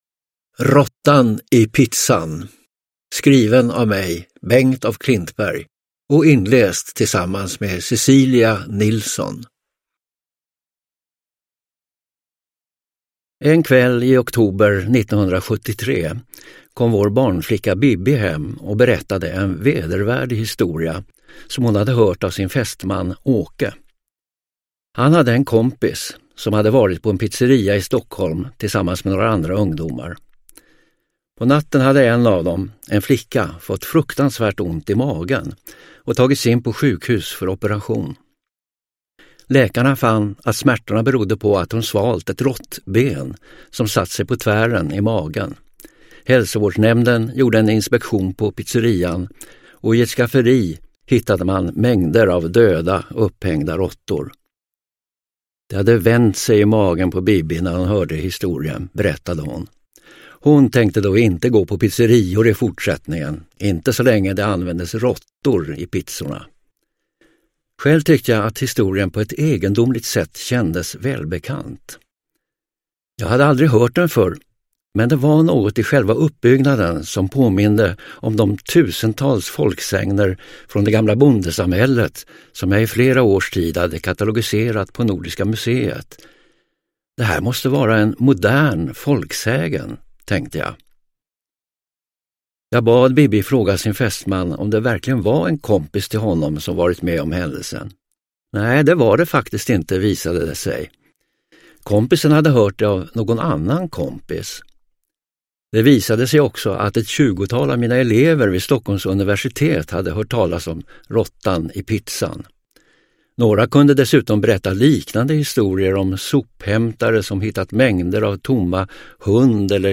Uppläsare: Anita Wall, Bengt af Klintberg